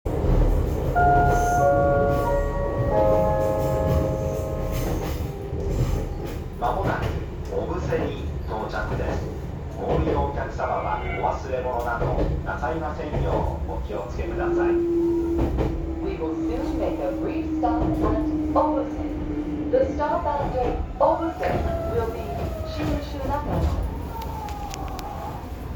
・2100系車内放送
JR東日本当時のイメージを残した放送になっており、車内チャイムもJR時代のメロディの音色違いとなっています。